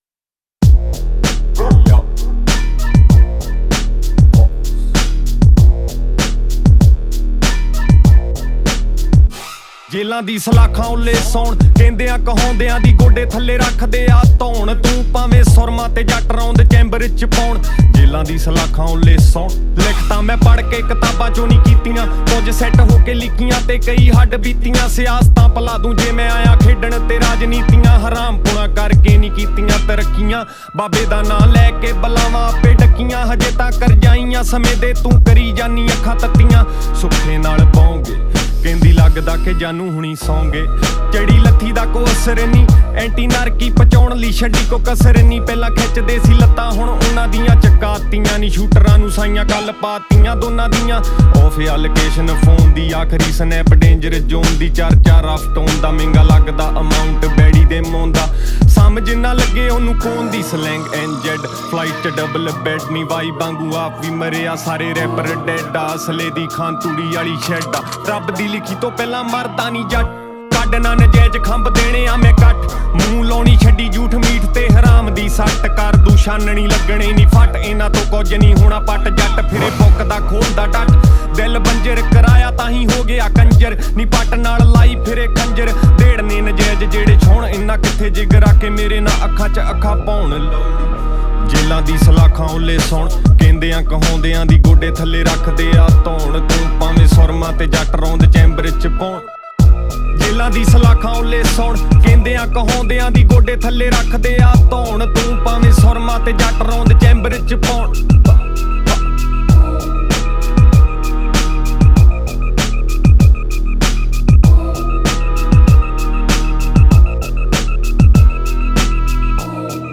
Category: Punjabi